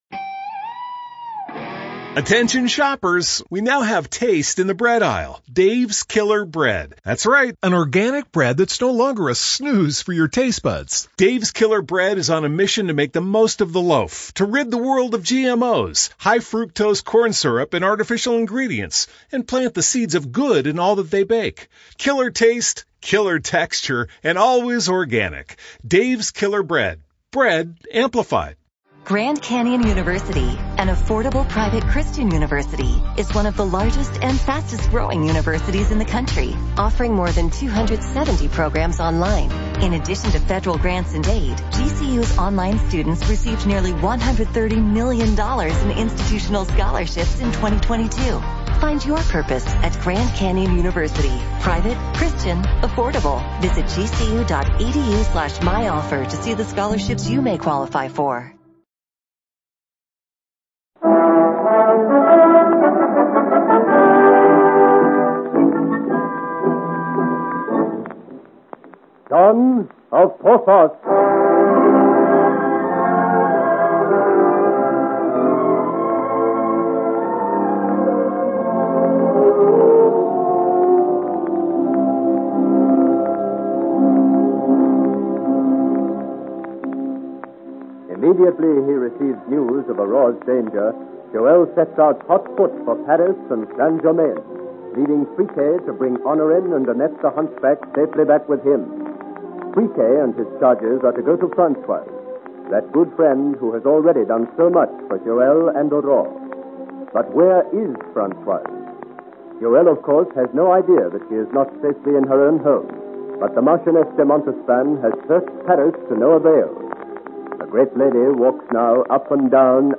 Genre: Adventure, Romance, Drama